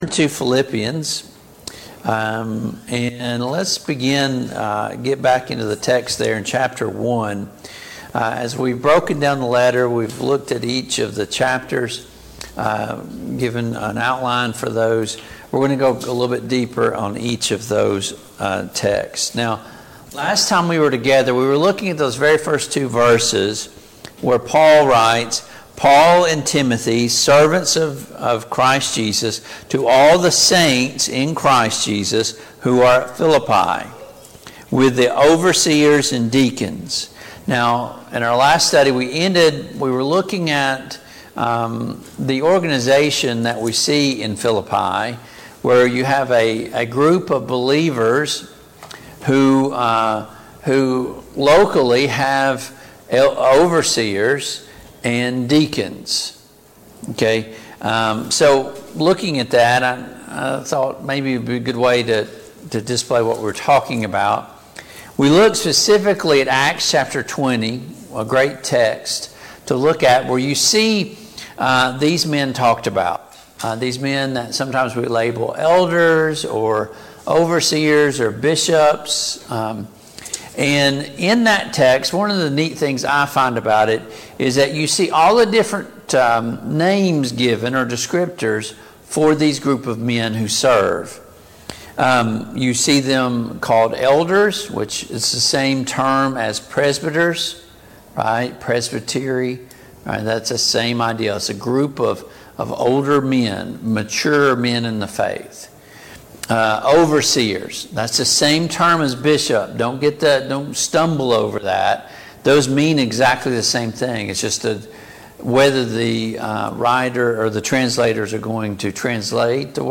Passage: Philippians 1, Philippians 2 Service Type: Mid-Week Bible Study